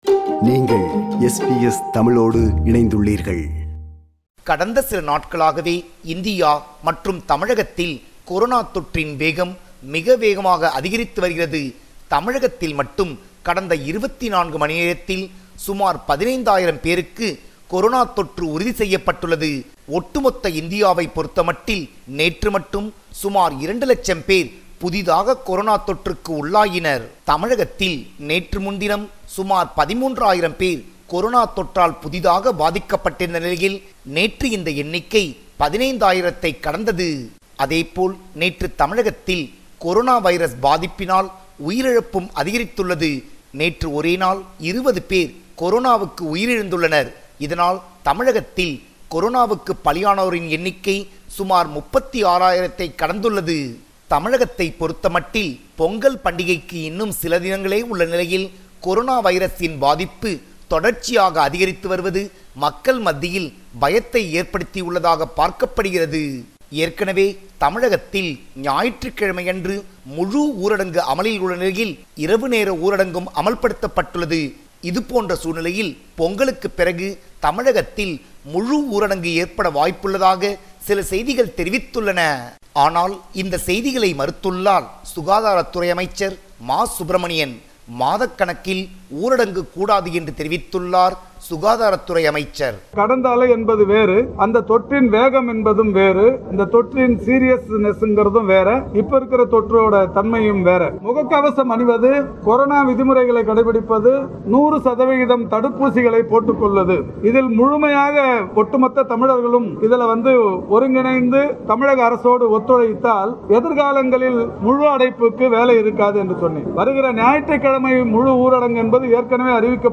compiled a report focusing on major events/news in Tamil Nadu